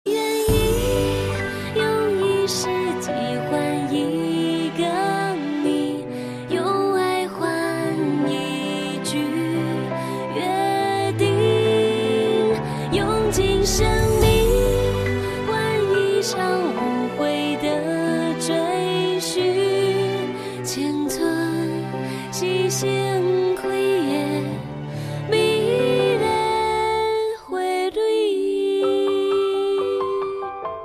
M4R铃声, MP3铃声, 华语歌曲 190 首发日期：2018-05-16 01:42 星期三